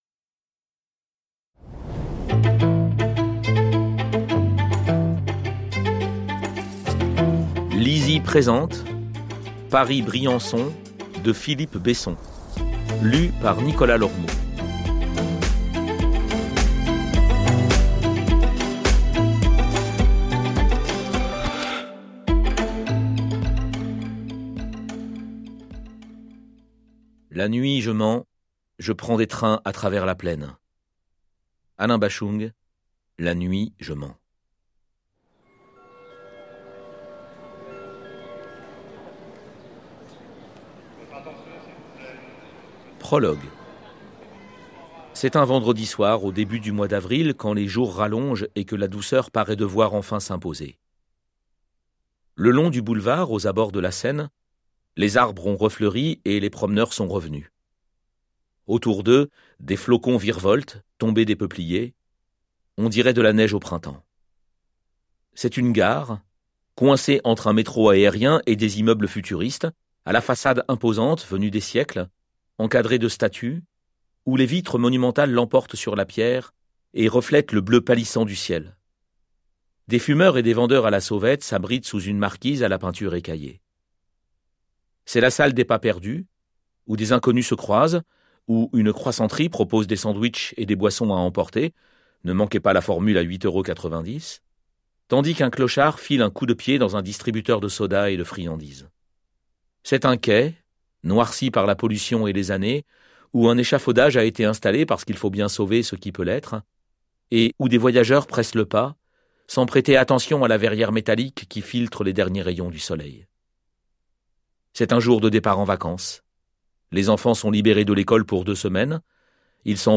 je découvre un extrait - Paris-Briançon de Philippe Besson